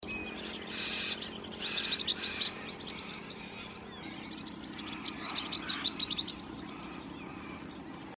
Catita Enana (Forpus xanthopterygius)
Fase de la vida: Adulto
Localización detallada: Laguna Curichi Cuajo
Condición: Silvestre
Certeza: Fotografiada, Vocalización Grabada